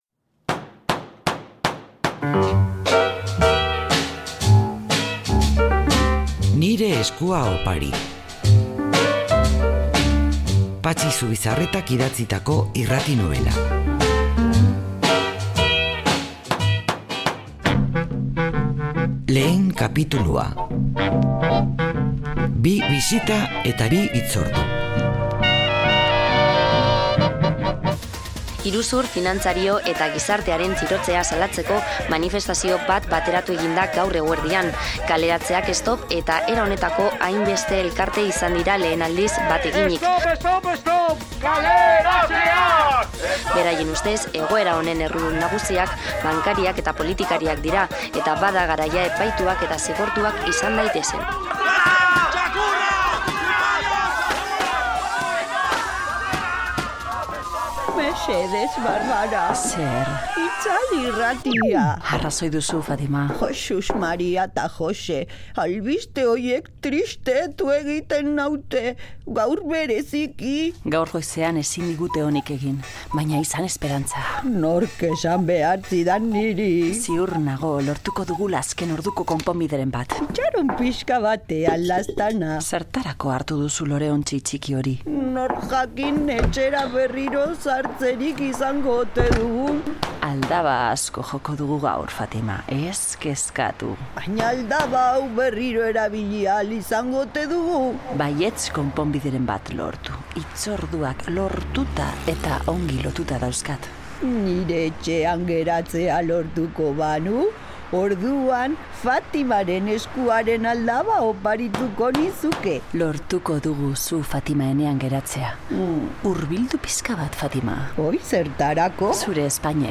Radio Vitoria RADIO-TEATRO-URALDE 'Nire eskua opari' (1. kapitulua) Publicado: 19/10/2015 17:31 (UTC+2) Última actualización: 27/07/2021 11:43 (UTC+2) Patxi Zubizarretak idatzitako irrati-nobela.